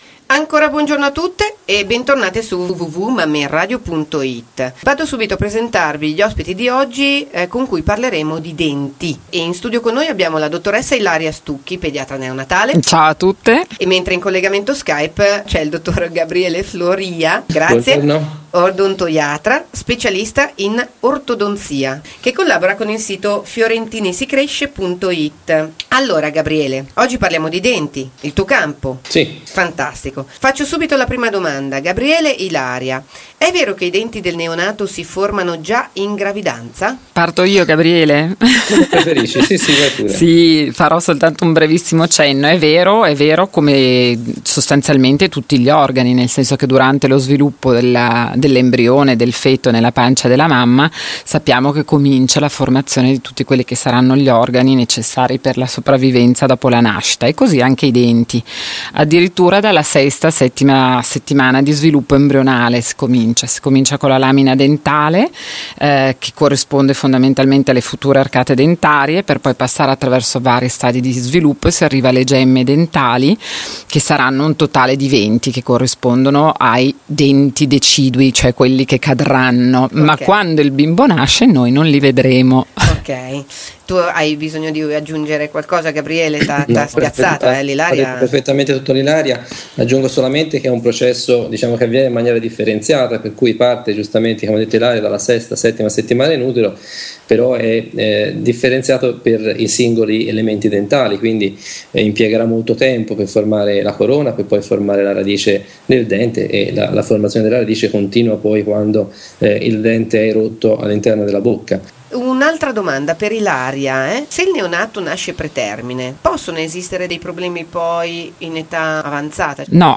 Ascolta l’intervista di Mamme in Radio: